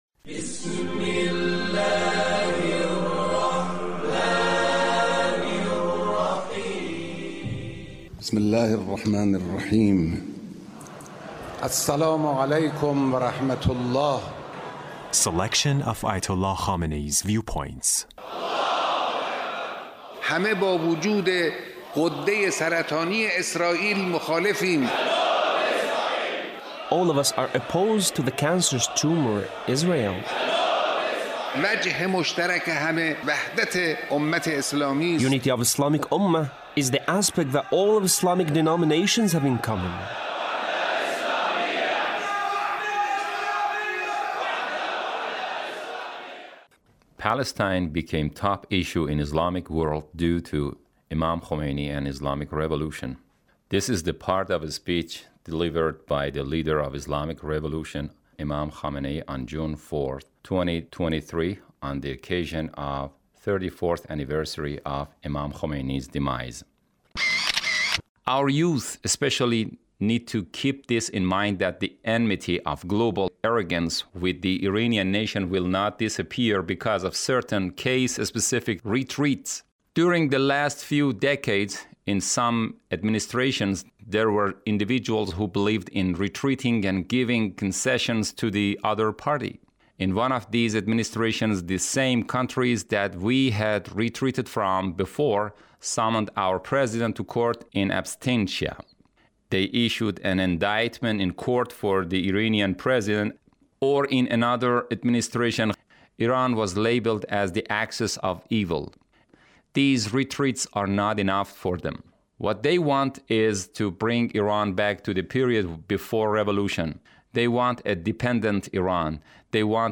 Leader's Speech on the occasion of the 34th ََAnniversary of Imam Khomeini’s Demise. 2023